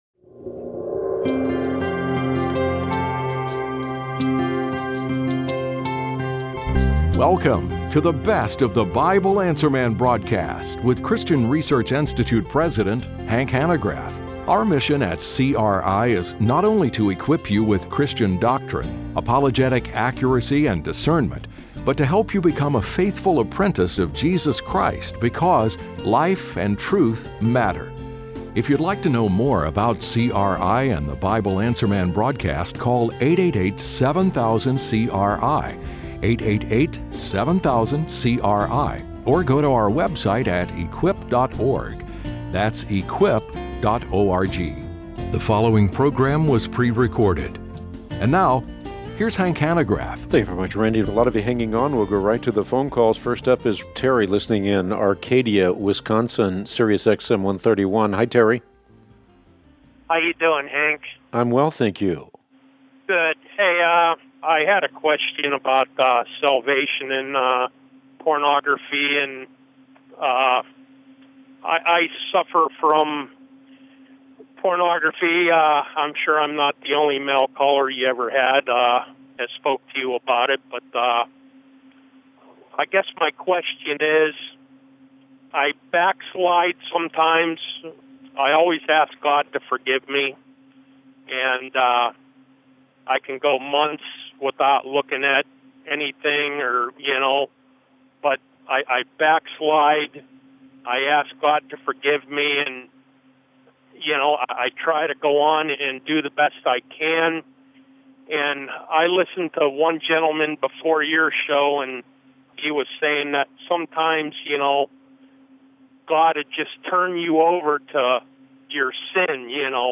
Best of BAM Q&A: Pornography, Holding Grudges, and Objective Morality | Christian Research Institute